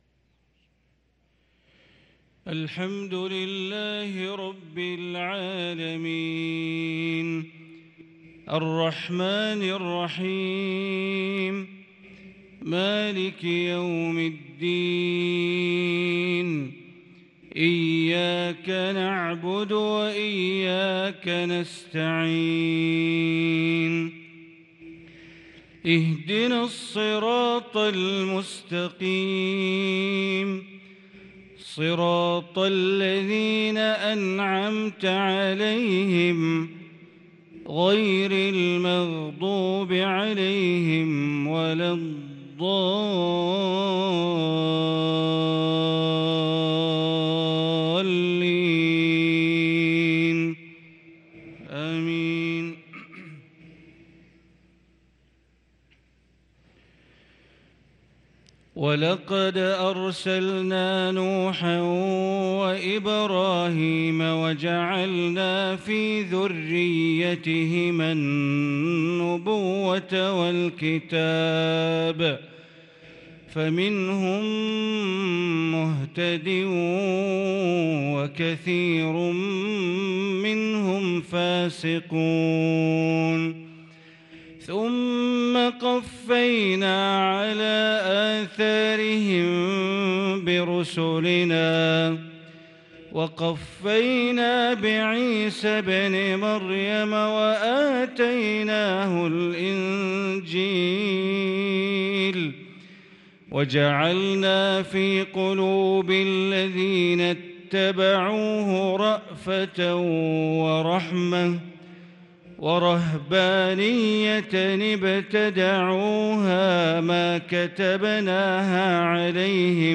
صلاة المغرب للقارئ بندر بليلة 18 ذو الحجة 1443 هـ